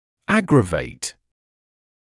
[‘ægrəveɪt][‘эгрэвэйт]усугублять, отягчать, углублять; ухудшаться, усиливаться (о патологии)